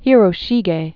(hîrō-shēgā, hērô-shēgĕ), Ando 1797-1858.